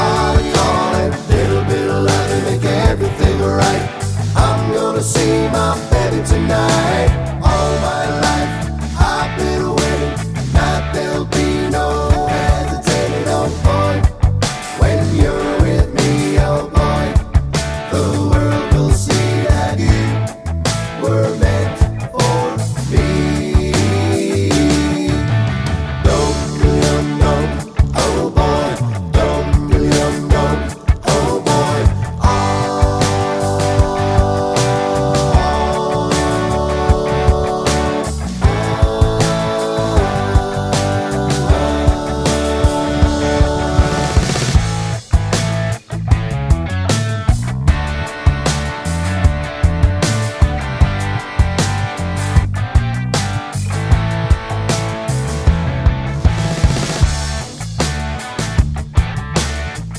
no Backing Vocals Glam Rock 2:53 Buy £1.50